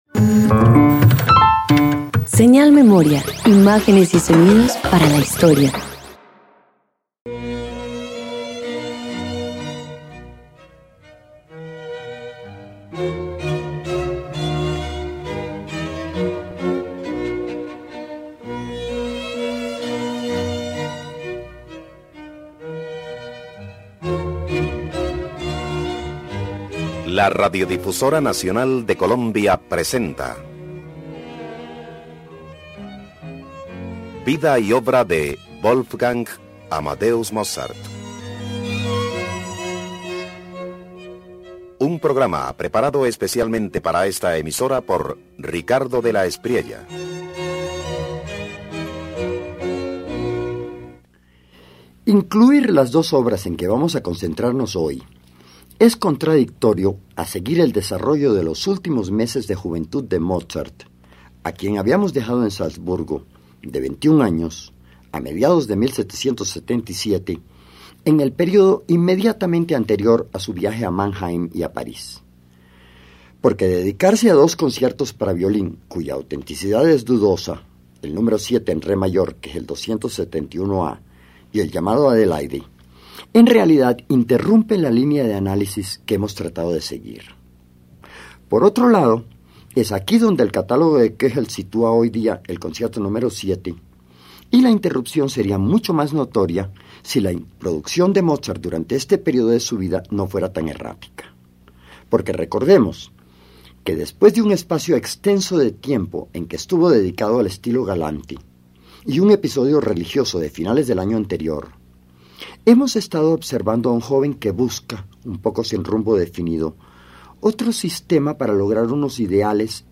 El Concierto para violín n.º 7 en re mayor, K271a, de autenticidad discutida, refleja un Wolfgang Amadeus Mozart en transición: entre Salzburgo y el viaje a Mannheim, combina virtuosismo y orquestación innovadora, anticipando su madurez sinfónica.